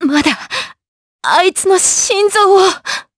Seria-Vox_Dead_jp.wav